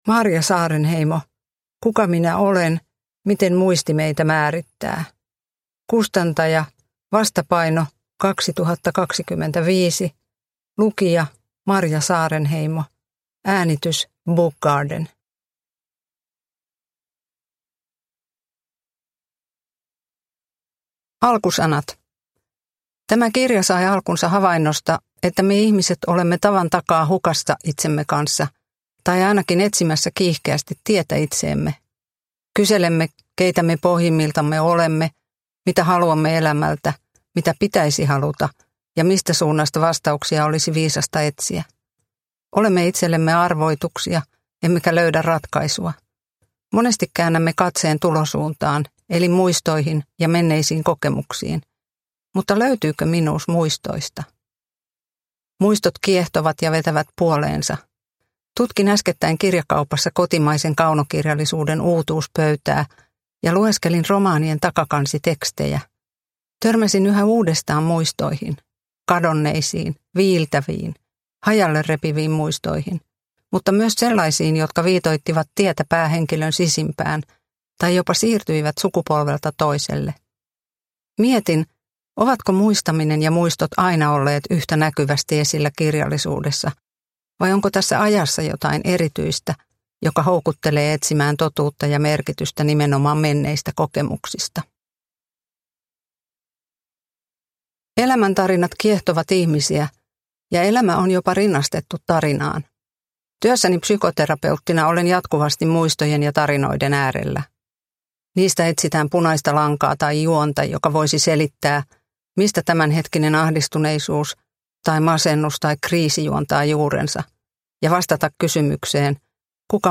Kuka minä olen? – Ljudbok